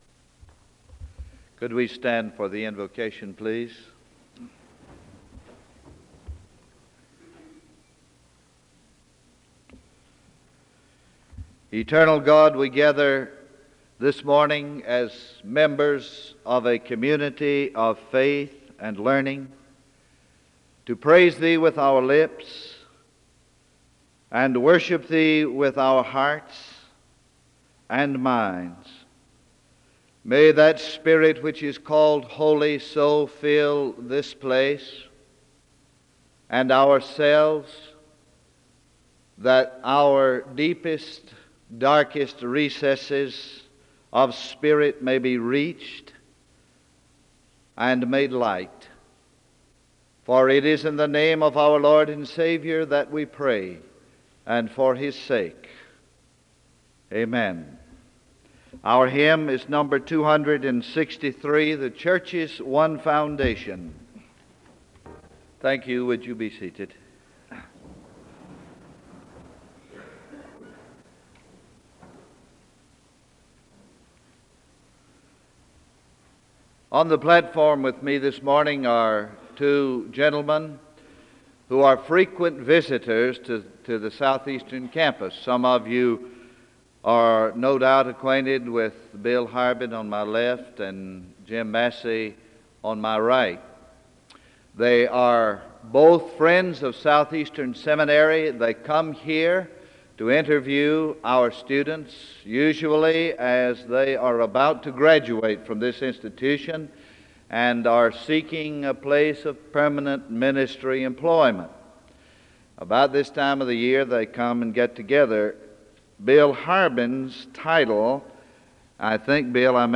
The service begins with a moment of prayer (0:00-1:00).
There is a Scripture reading from II Corinthians 6 and a moment of prayer (4:21-8:35). The choir sings a song of worship (8:36-10:50).